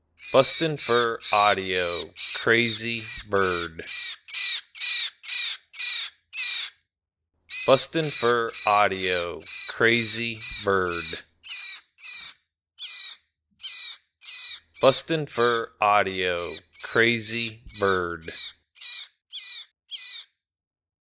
Small bird in distress, excellent for calling all predators.